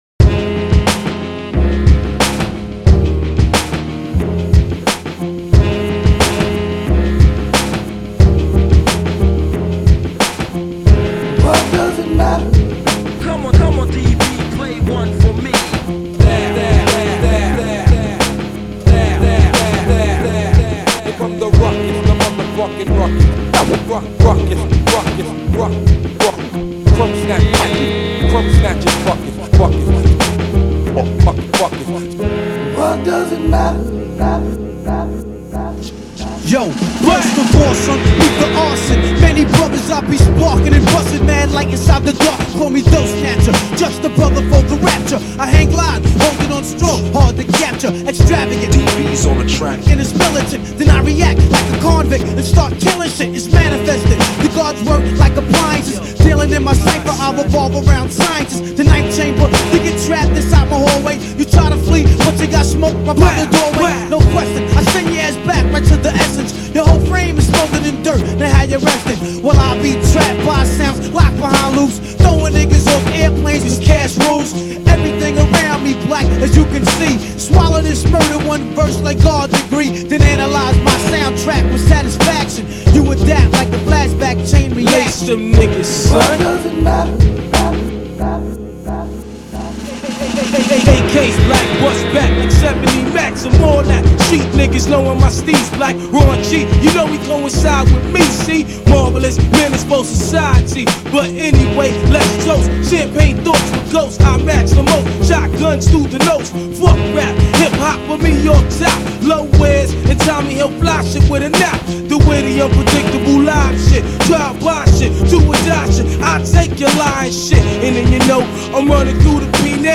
gritty and soulful